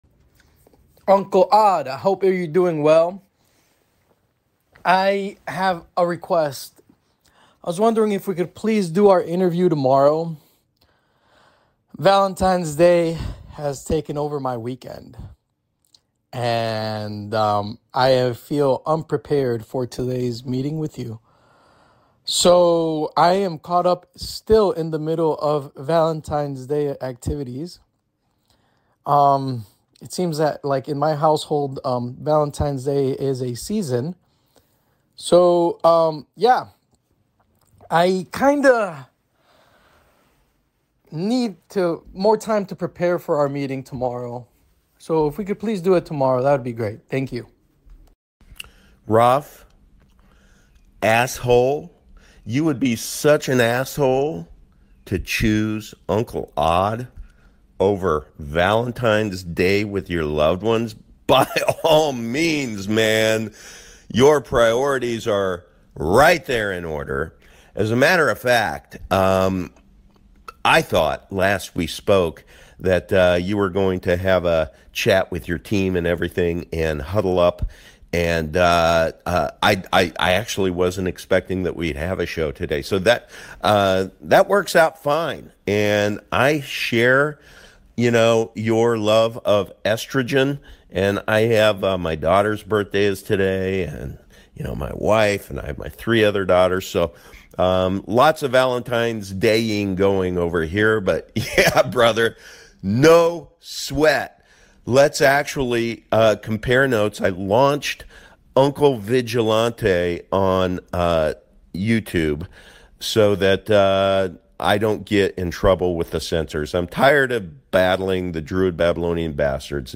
Conversations like THIS ONE is how you operationalize ideas!